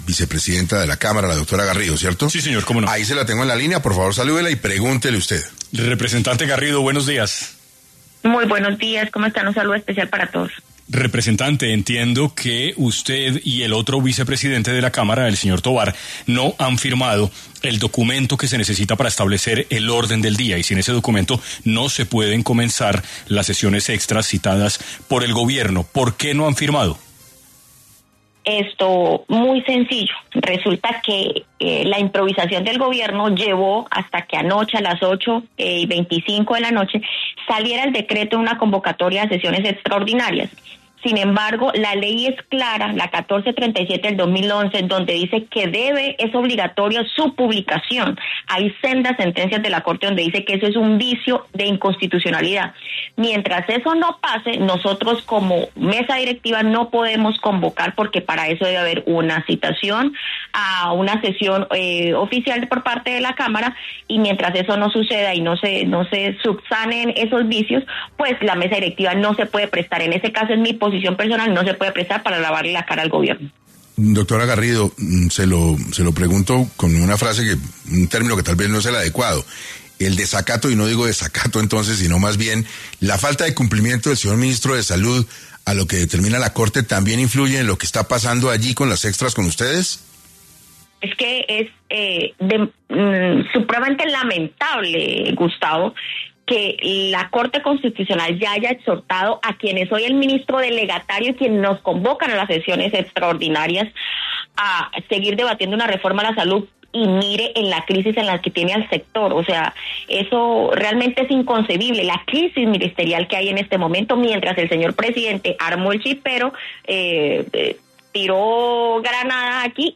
En Caracol Radio estuvieron los congresistas Lina Garrido y Andrés Forero
En entrevista para 6AM, la representante a la cámara explicó por qué no ha firmado este documento.